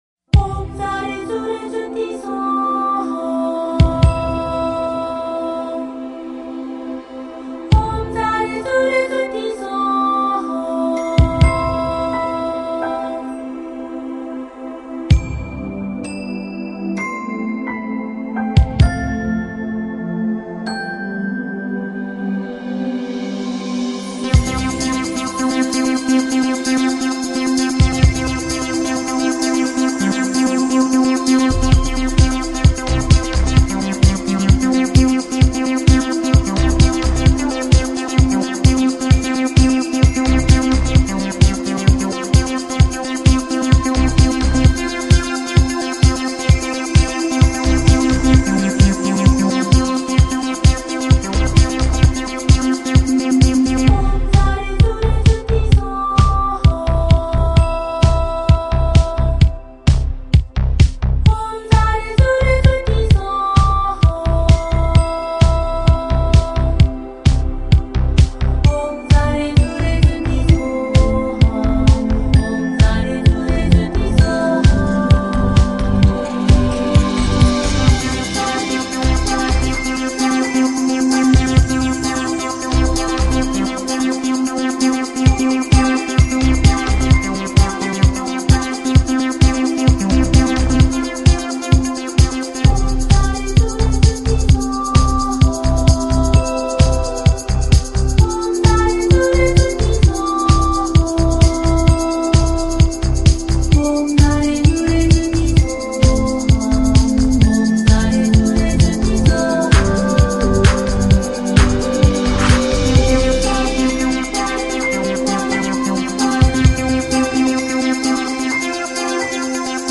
佛音 凡歌 佛教音乐 返回列表 上一篇： 聆听(大悲咒